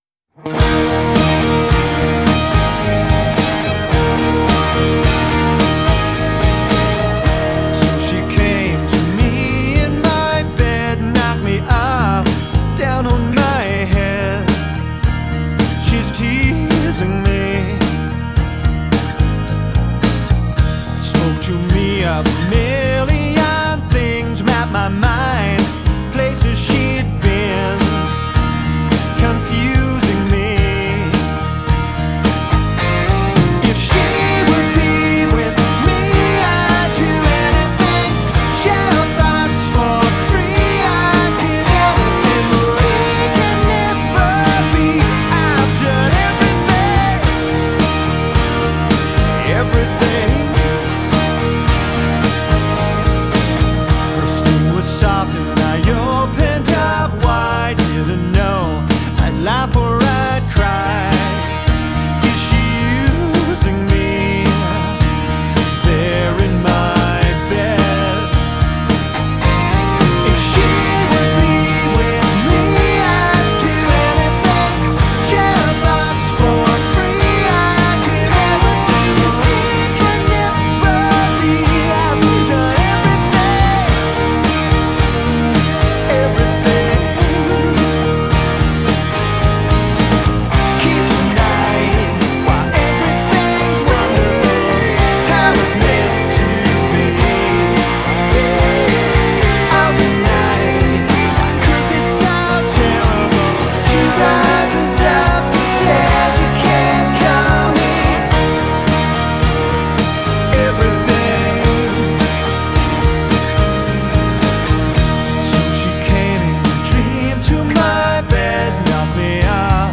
Contemporary Pop
Genre: Spiritual: Contemporary Gospel